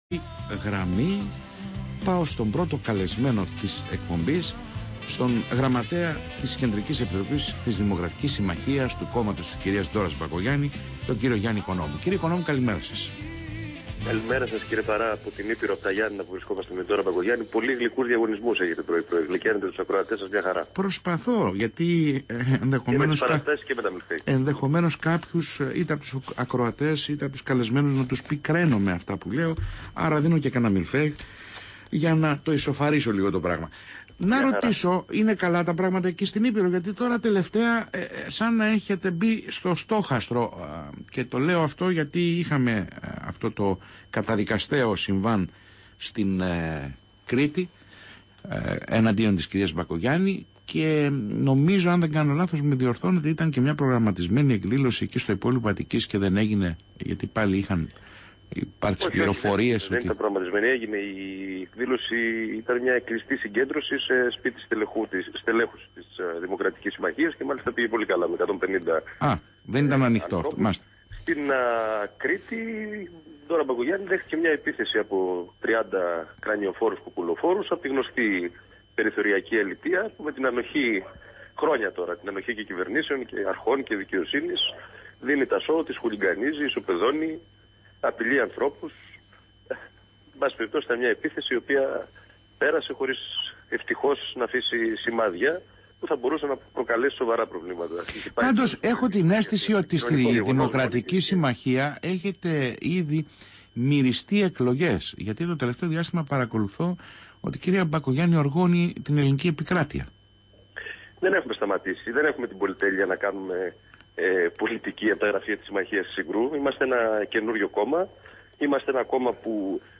Συνέντευξη Γιάννη Οικονόμου στο ραδιόφωνο Flash 96.0 | Κίνημα
Ακούστε τη συνέντευξη του γραμματέα της Κεντρικής Επιτροπής, Γιάννη Οικονόμου, στο ραδιόφωνο Flash 96.0